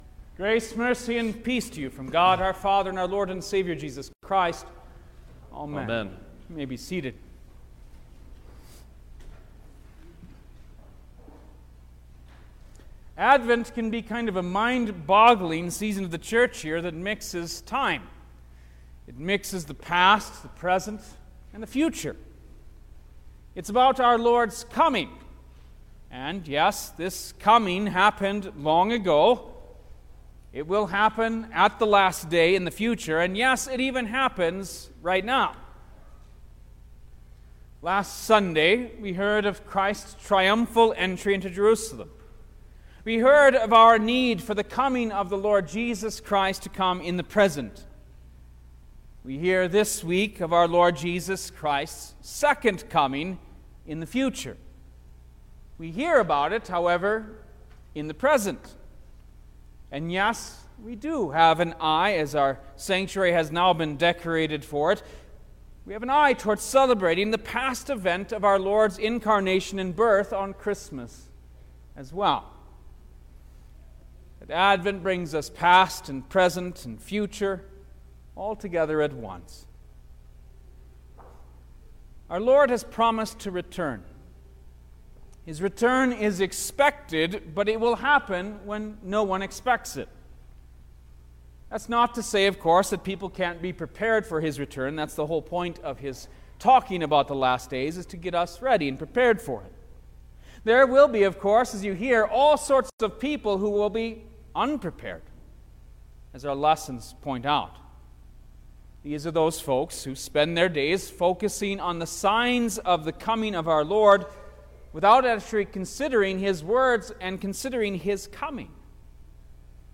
December-4_2022_Second-Sunday-in-Advent_Sermon-Stereo.mp3